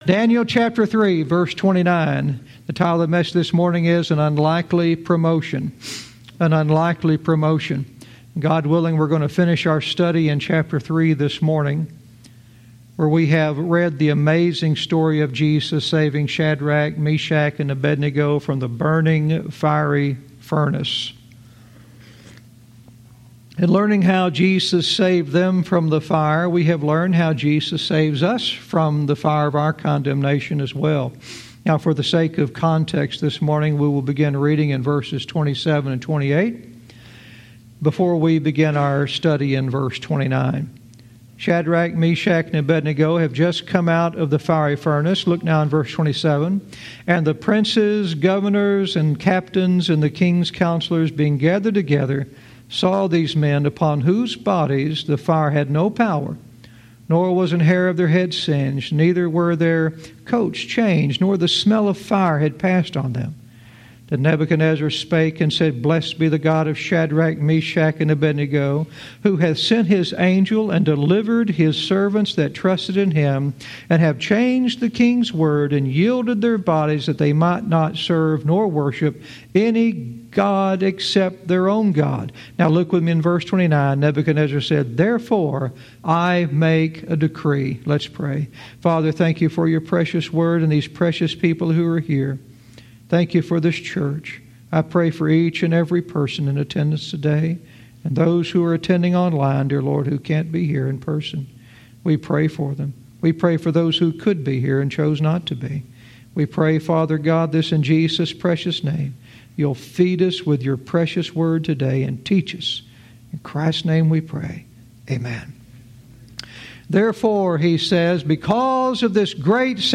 Verse by verse teaching - Daniel 3:29 "An Unlikely Promotion"